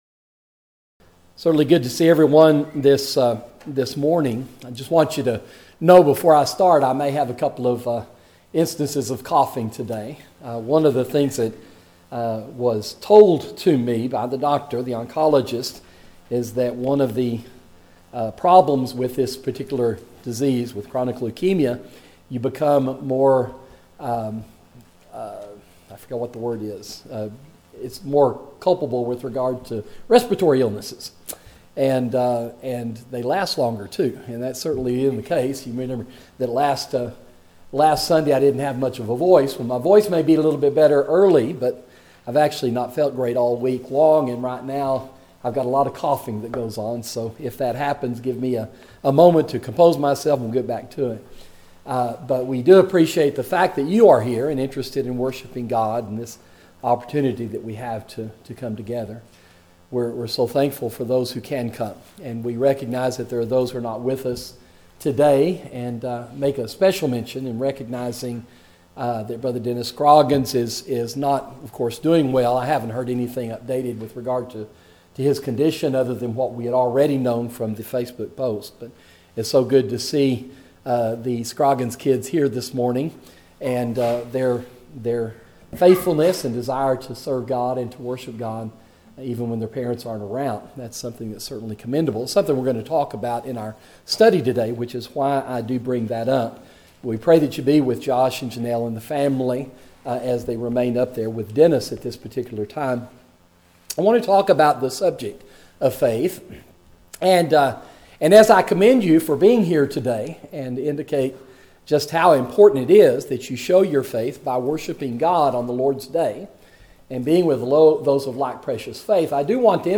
Sermon: Pervasive Faith